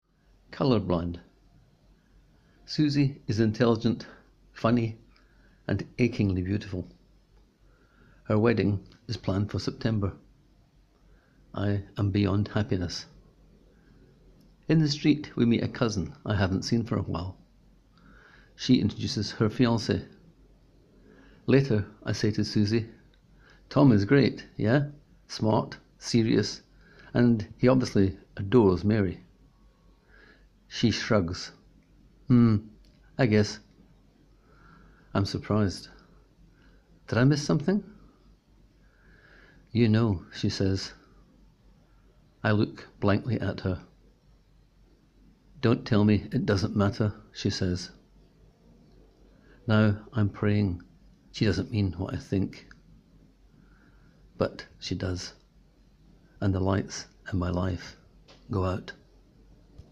Click here to hear the writer read his words: